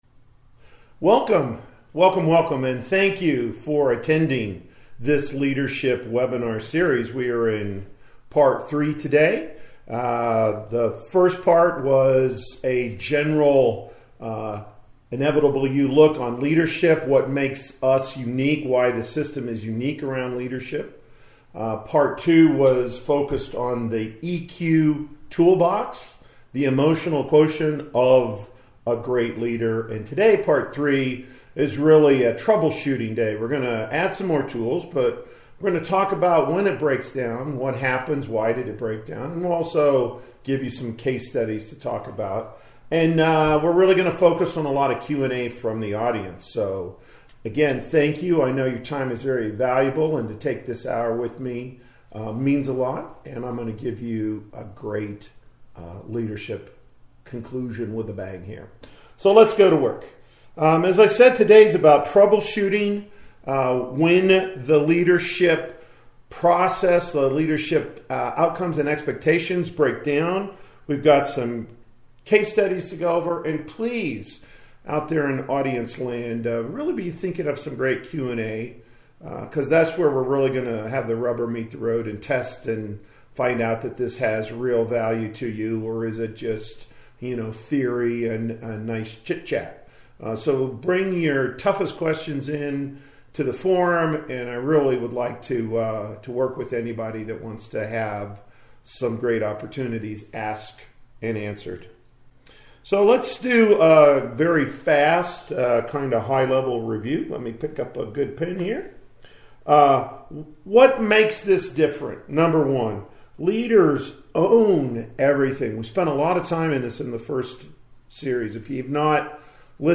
This mini-series comes to an end with case studies and tools to help you apply this in the real world. It is also the most Q & A in-depth webinar with both you preparing questions and reacting to the case studies with your observations and thoughts.